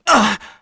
Neraxis-Vox_Damage_kr_01.wav